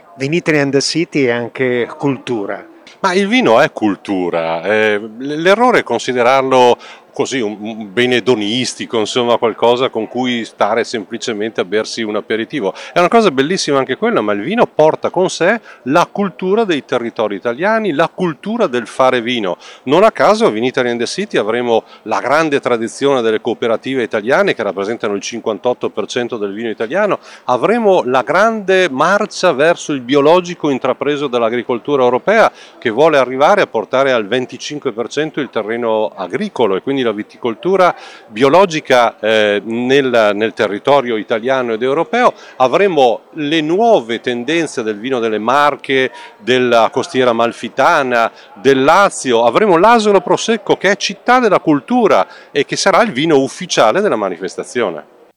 Le interviste del nostro corrispondente